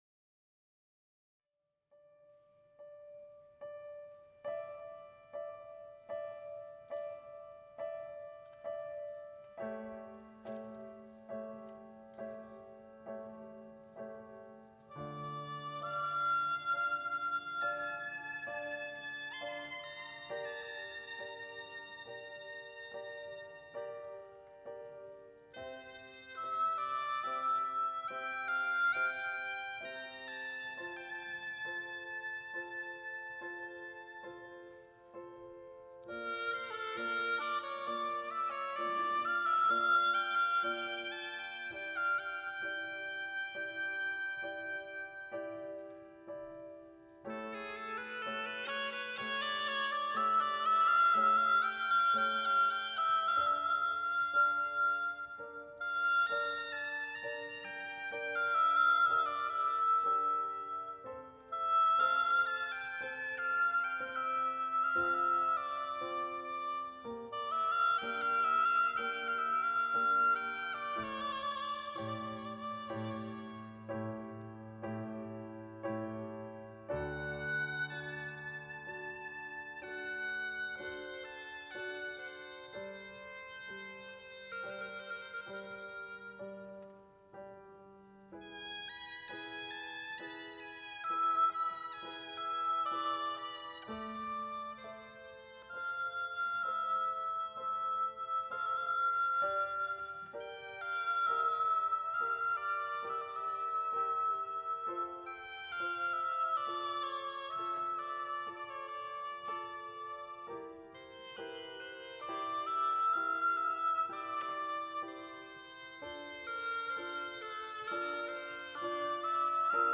2/18/2026-Ash Wednesday
Sermon